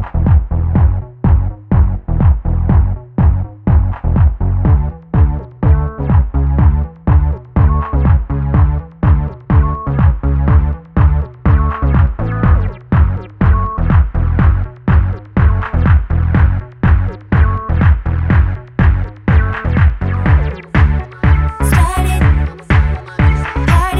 R'n'B / Hip Hop